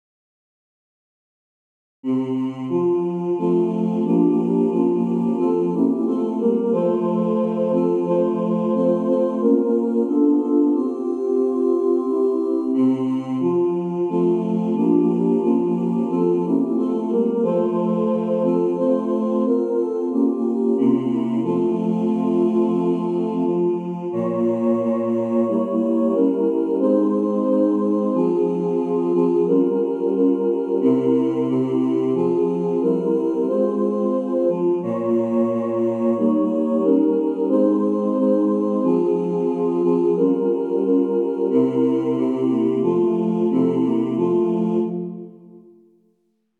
Voicing/Instrumentation: SATB , SATB quartet